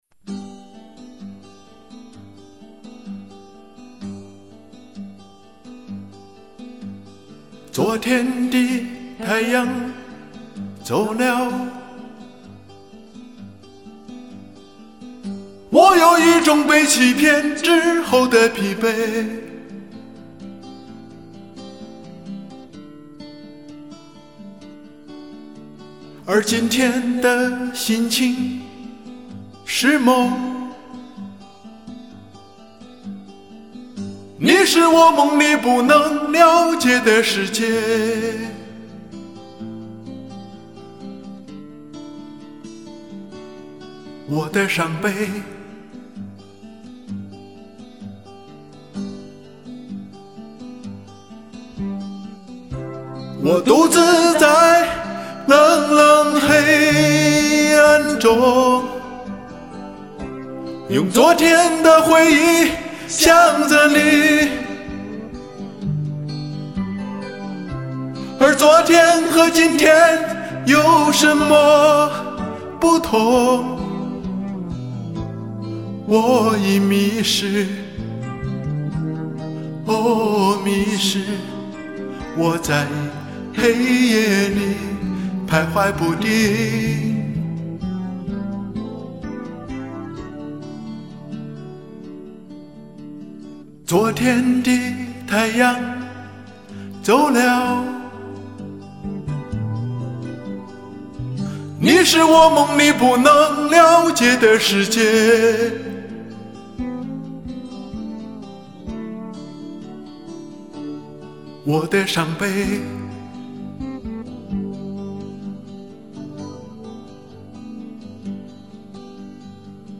很伤感那～～～～-_-
是什么让您的歌声听起来如此无奈与凄凉~~~~